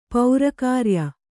♪ paura kārya